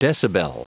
Prononciation du mot decibel en anglais (fichier audio)
Prononciation du mot : decibel
decibel.wav